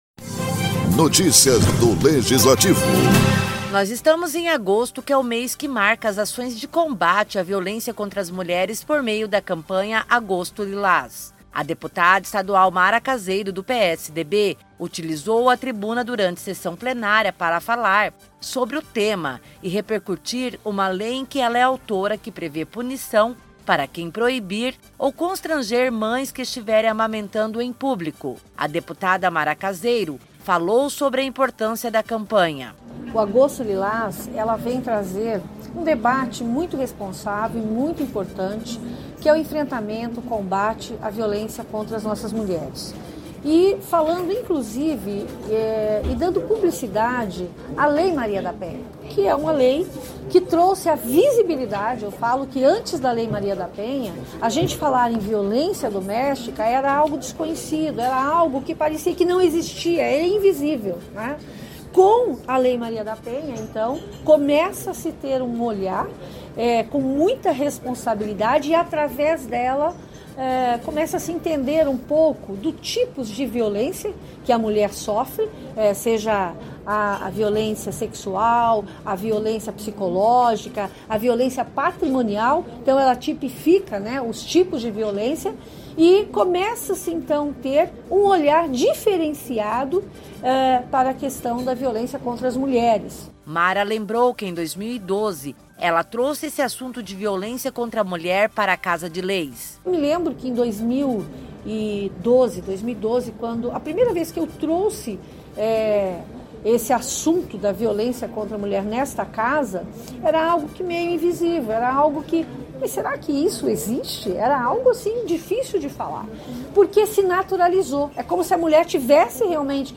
A deputada Mara Caseiro, do PSDB, fez uso da tribuna na sessão ordinária para destacar as ações da Campanha Agosto Lilás, que visa o enfrentamento à violência doméstica e familiar contra a mulher e intensifica a divulgação da Lei Maria da Penha.